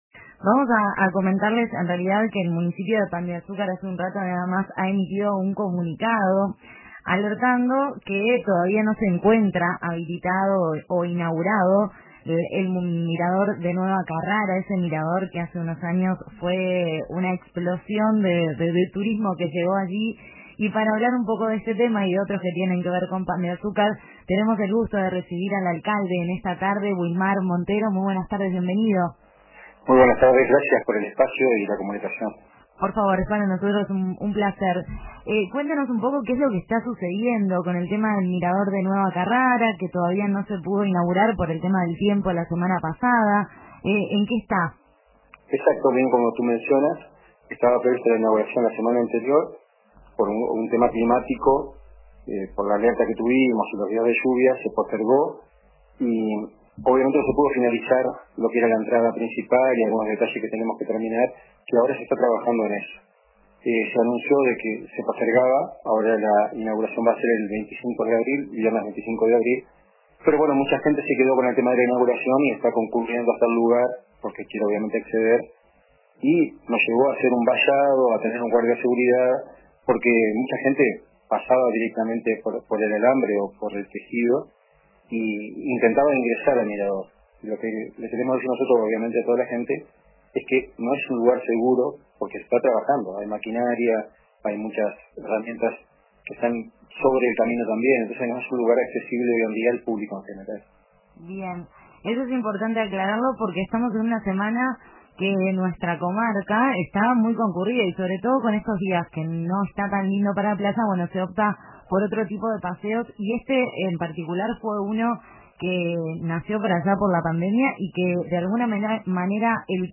La esperada inauguración de los miradores en la zona de Nueva Carrara fue reprogramada para el viernes 25 de abril, debido a las inclemencias del tiempo y la necesidad de culminar trabajos pendientes. Así lo confirmó el alcalde de Pan de Azúcar, Wilmar Montero, en entrevista con el programa La Tarde de RBC.